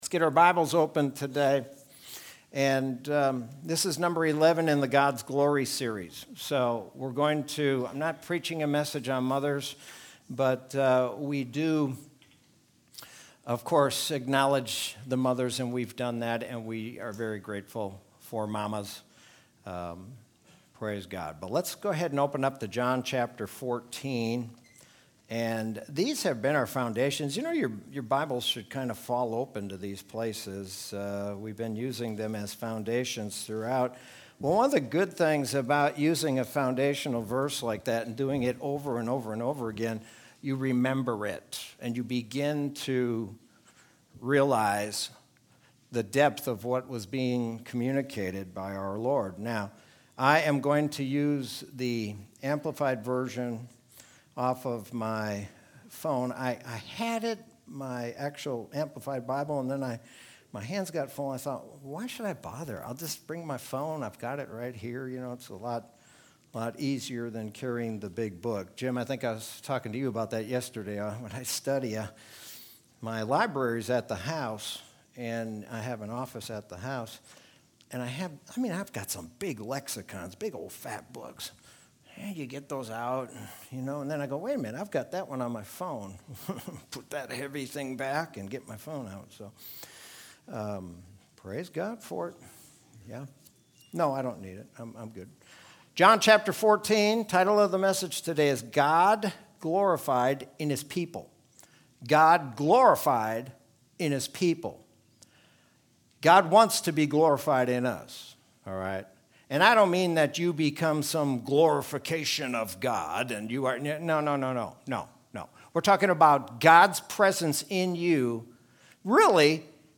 Sermon from Sunday, May 9th, 2021.